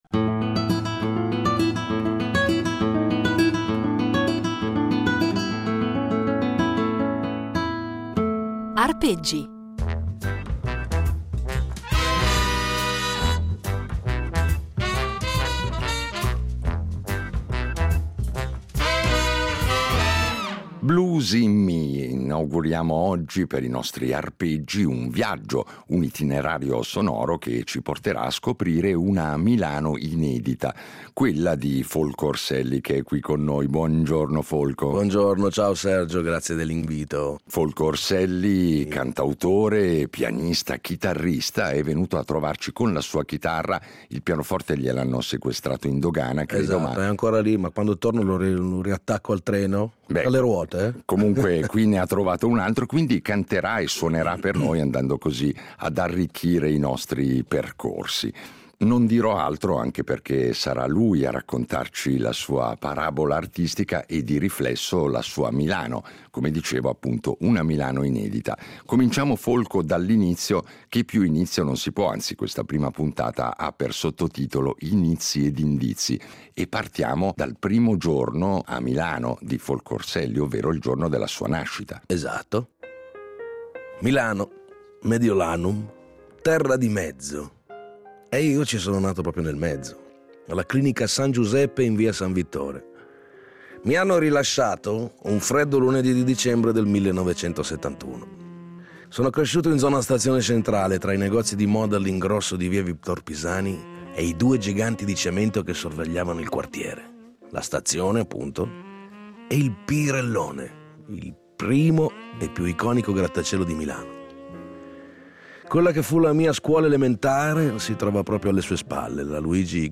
Un itinerario impreziosito, in ogni puntata, da un brano eseguito solo per noi, ai nostri microfoni.